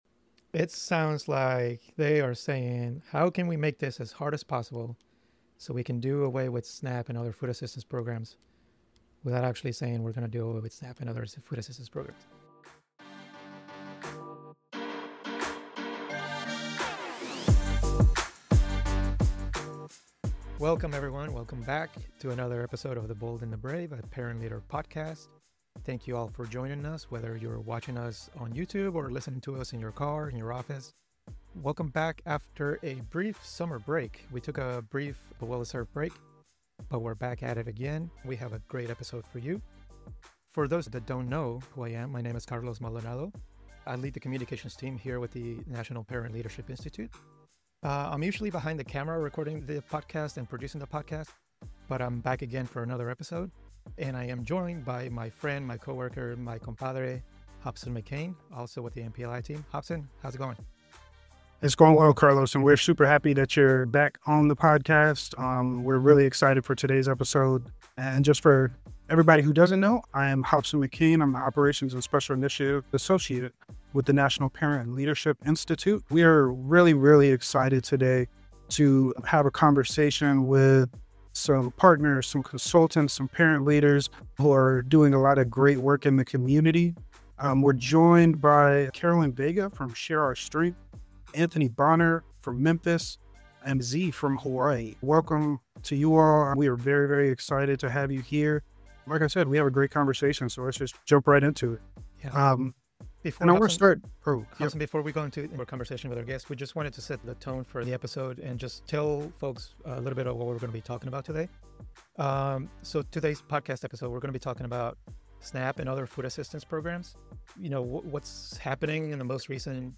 Join two parent leaders from the National Parent Leadership Institute (NPLI) as they share stories and tips on how to use your voice as a parent and caregiver in the change-making space.